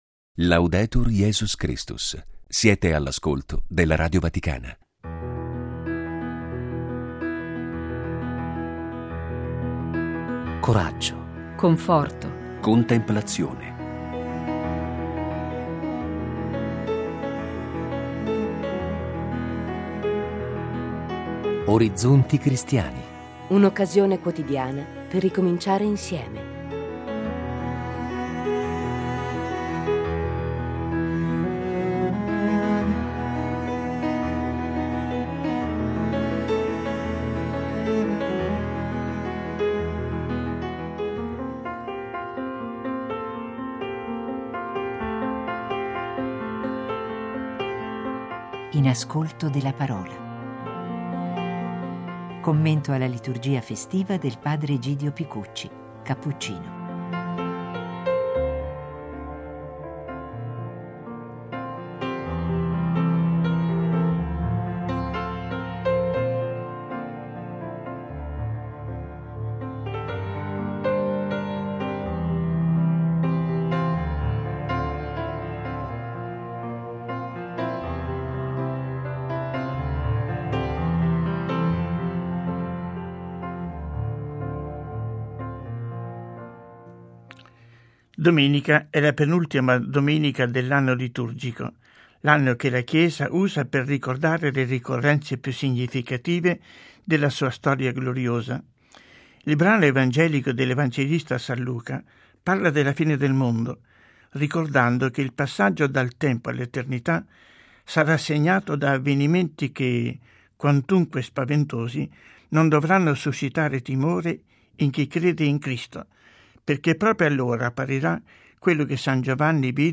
Lo spazio dedicato alla meditazione quotidiana il venerdì ospita il commento alla liturgia festiva